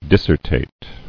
[dis·ser·tate]